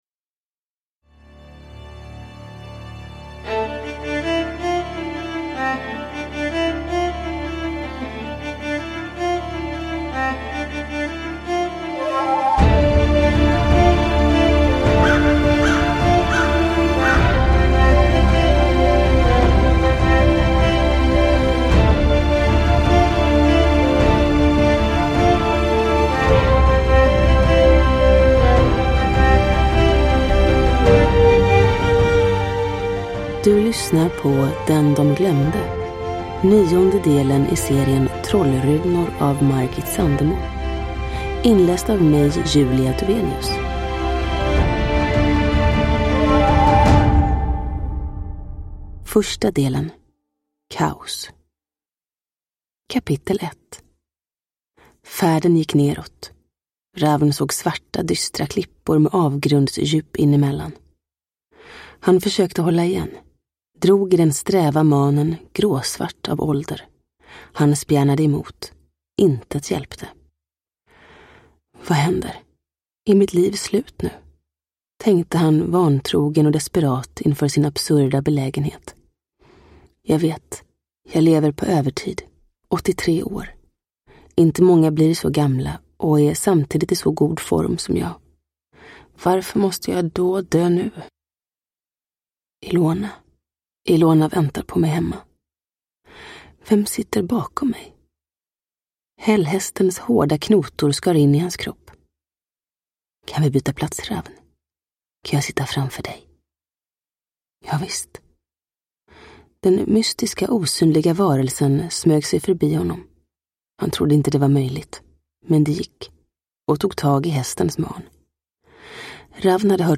Den de glömde – Ljudbok
Uppläsare: Julia Dufvenius